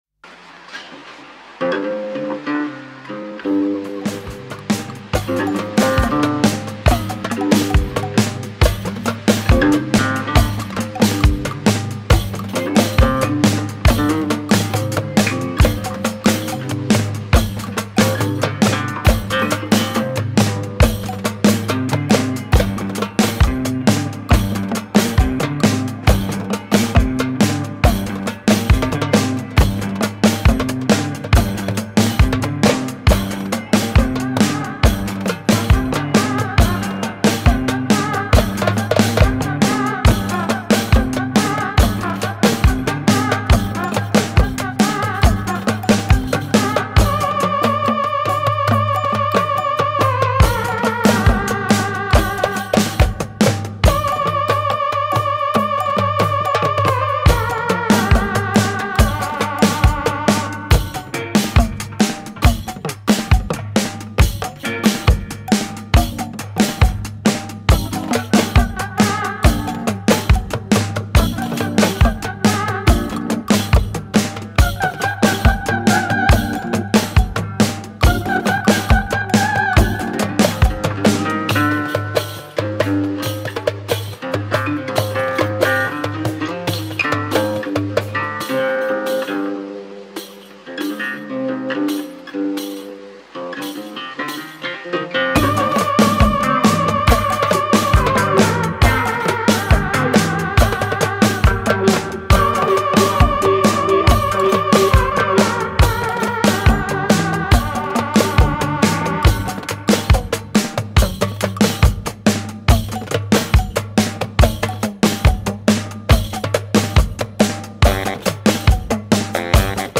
instrumental stomper